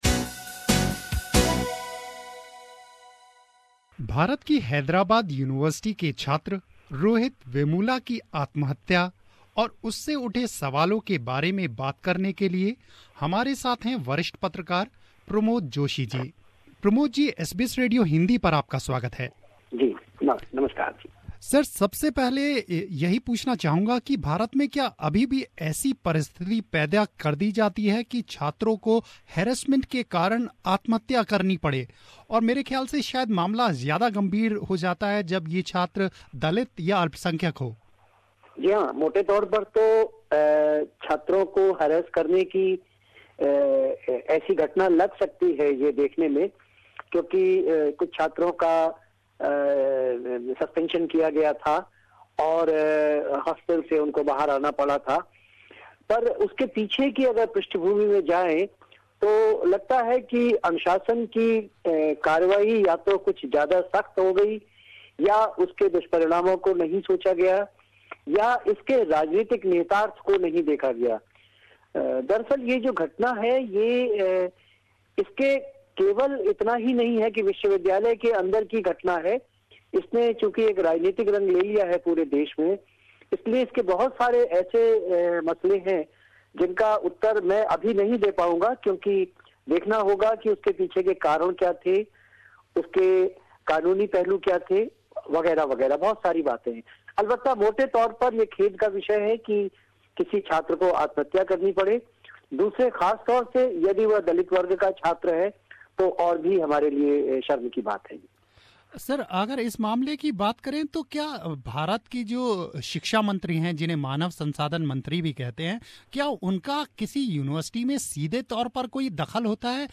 Talks to Senior Journalist